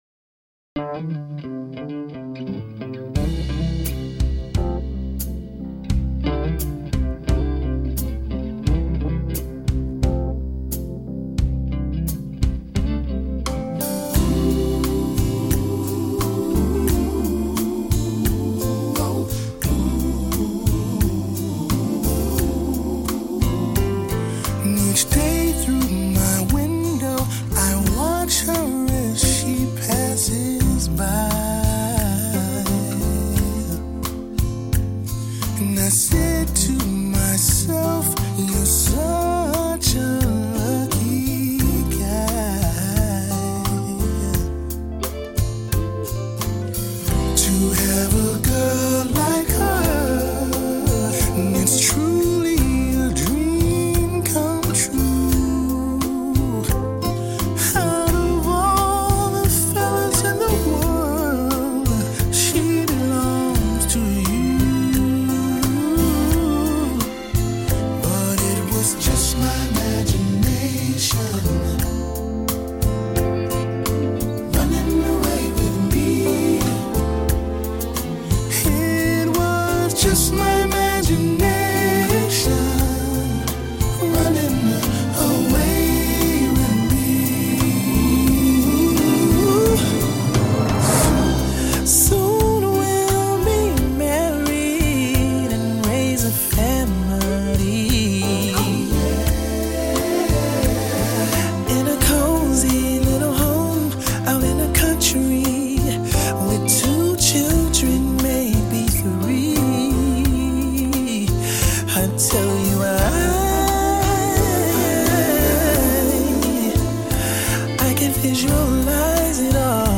Soul and sweet !